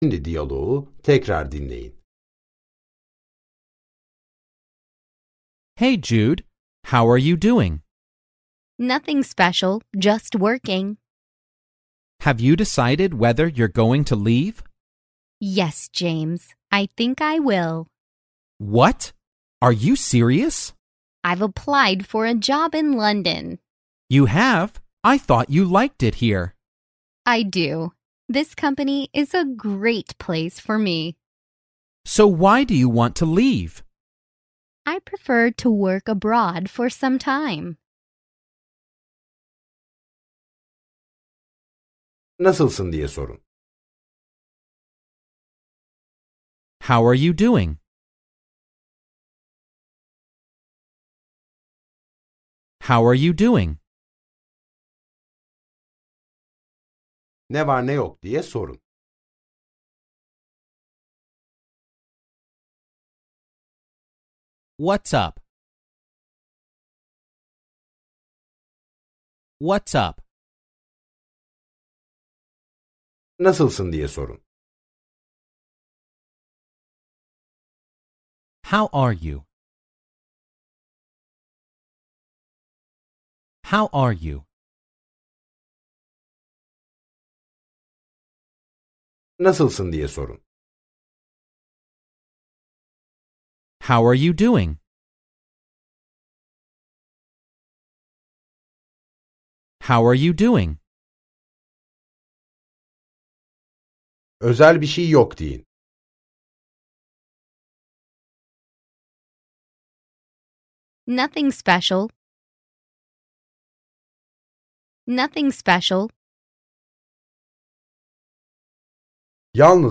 Az sonra İngilizce öğrenmenin keyifli dünyasına adım atacaksınız. Dersler boyunca sizi yönlendiren bir kişisel eğitmeniniz olacak. Ana dili İngilizce olan iki kişi de sürekli diyalog halinde olacaklar. Yönlendirmeniniz size söz verdikçe gerekli tekrarları yapın ve sorulan sorulara cevap verin.